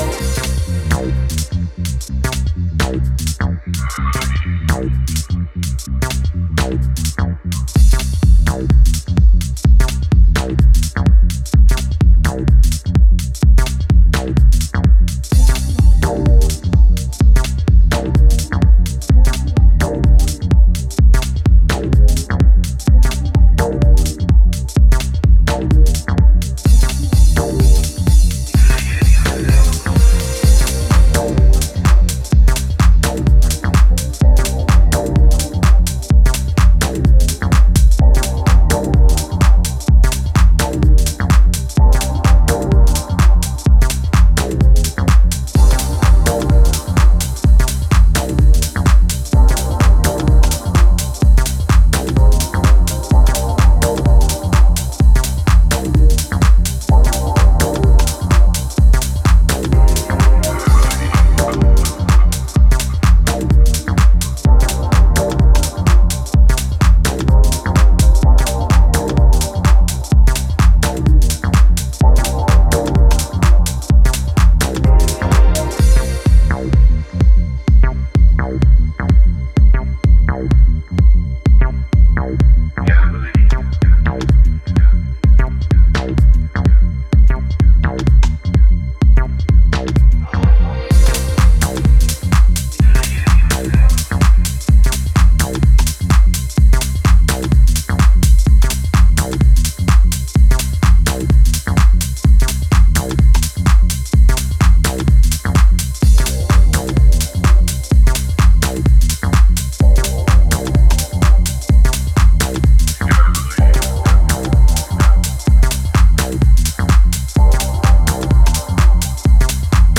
west coast house sound